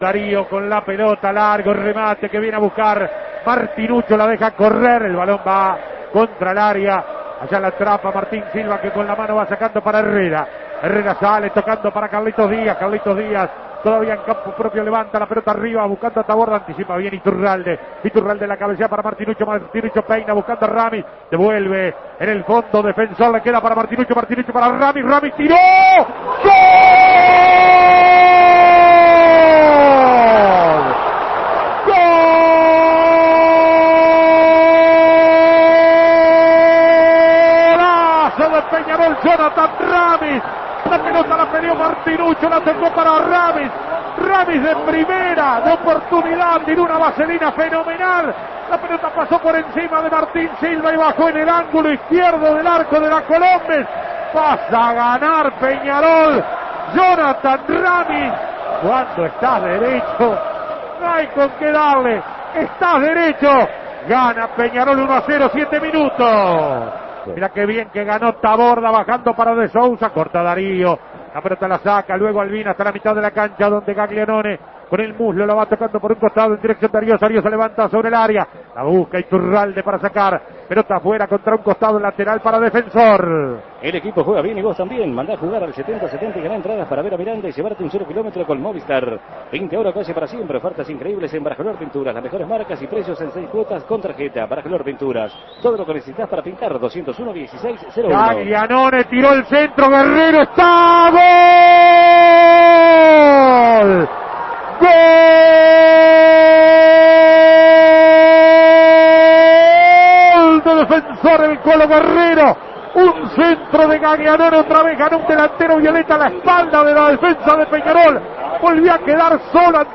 Goles y comentarios